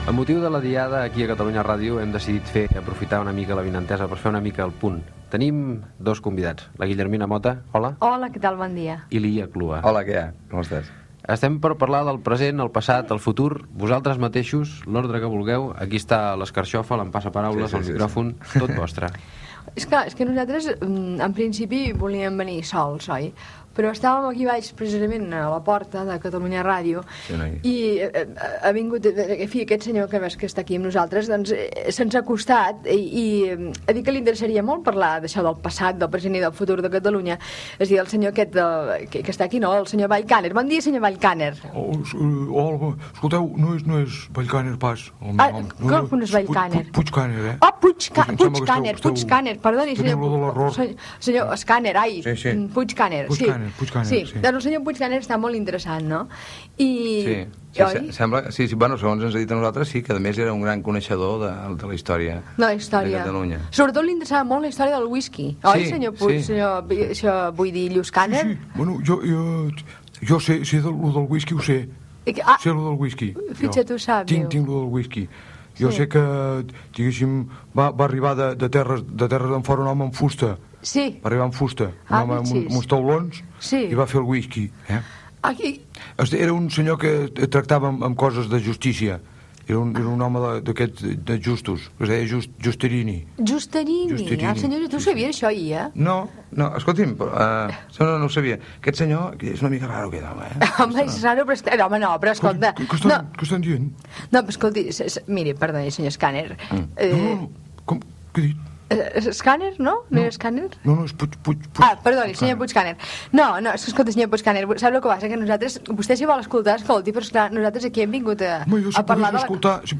El passat present i futur de Catalunya amb la intervenció del senyor Puig Cahner (paròdia del conseller de Cultura Max Cahner) Gènere radiofònic Entreteniment